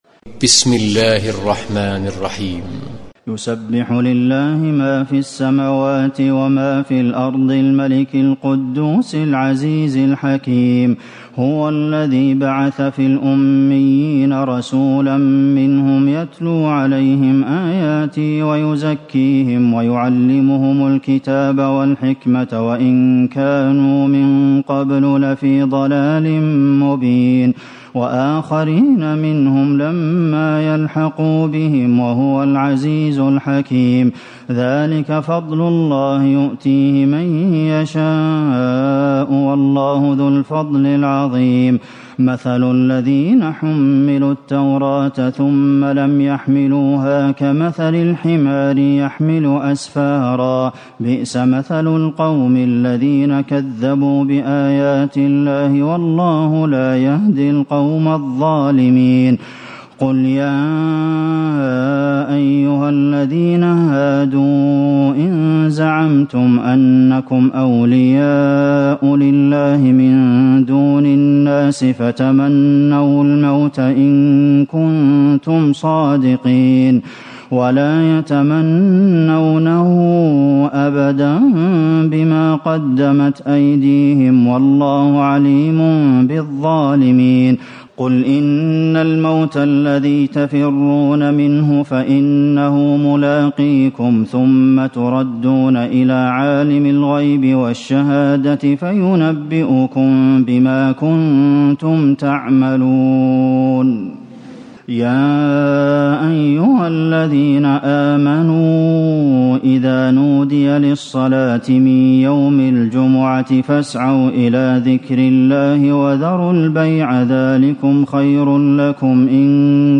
تراويح ليلة 27 رمضان 1437هـ من سورة الجمعة الى التحريم Taraweeh 27 st night Ramadan 1437H from Surah Al-Jumu'a to At-Tahrim > تراويح الحرم النبوي عام 1437 🕌 > التراويح - تلاوات الحرمين